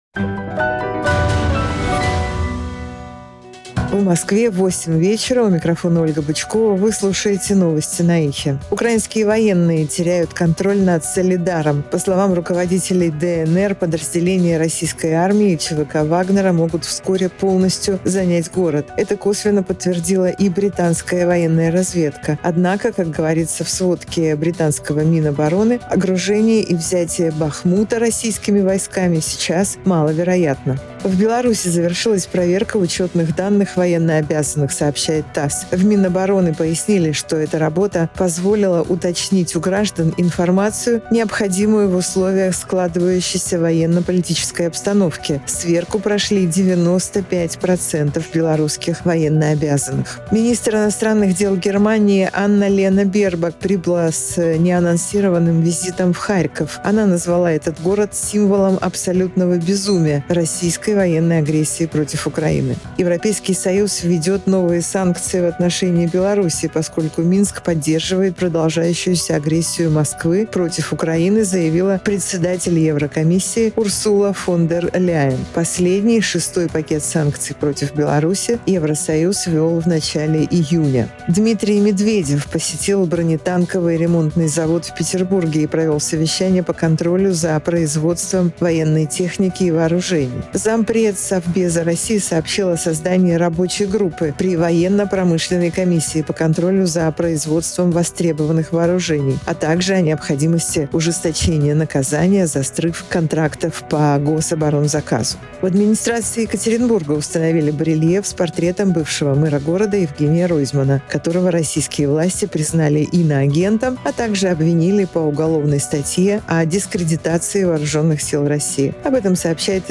Слушайте свежий выпуск новостей «Эха»…
Новости